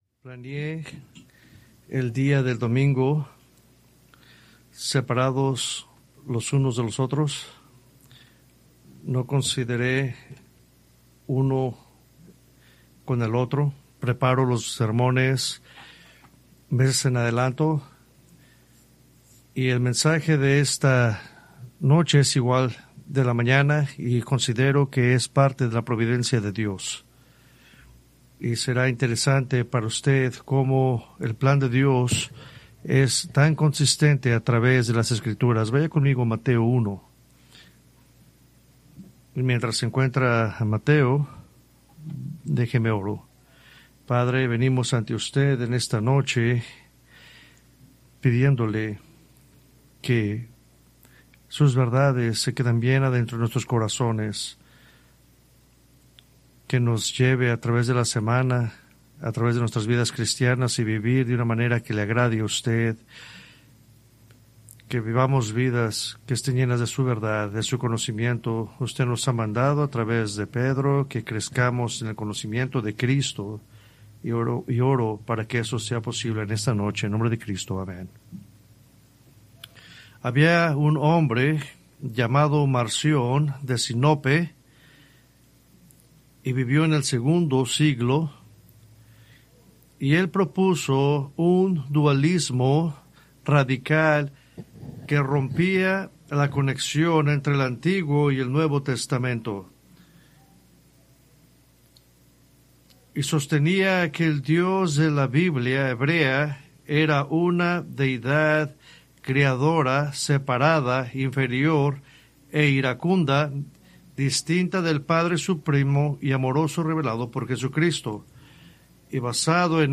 Preached December 21, 2025 from Escrituras seleccionadas